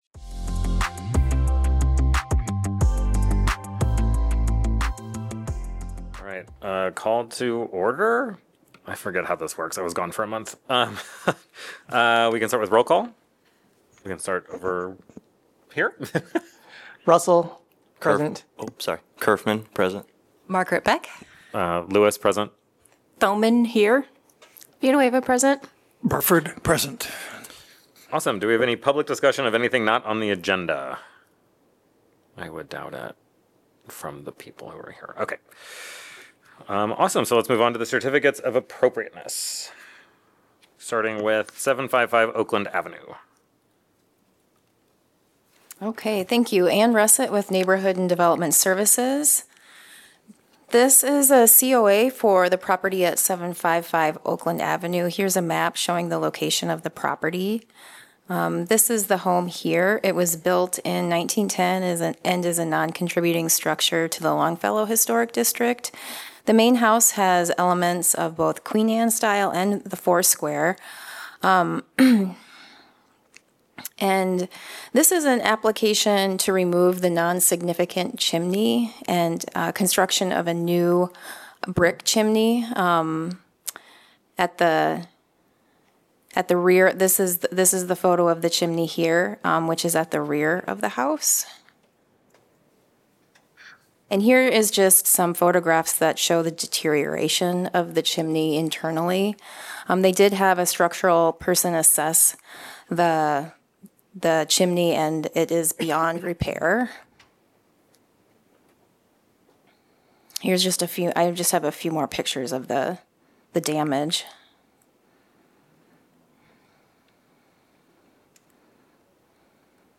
Regular meeting of the Iowa City Historic Preservation Commission.